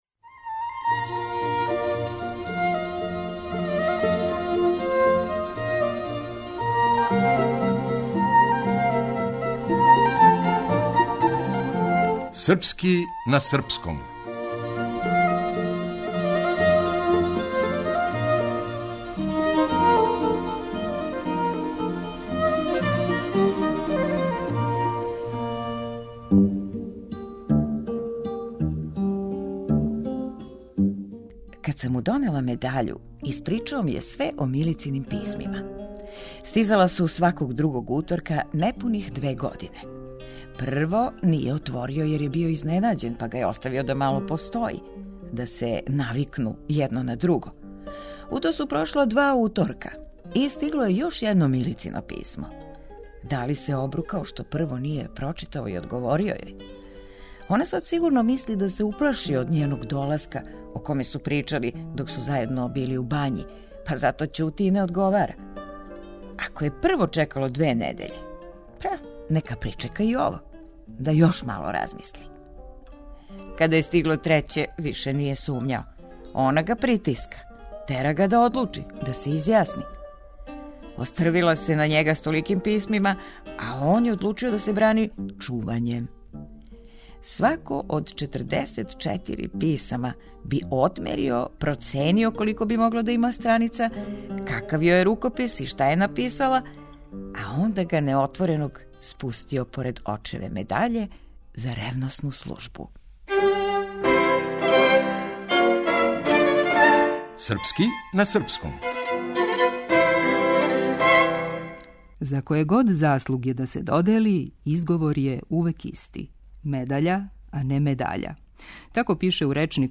Драмски уметник